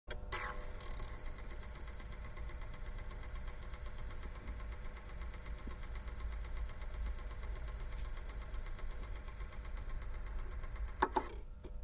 Звуки CD-плеера
Звук лазера при поиске трека в CD-проигрывателе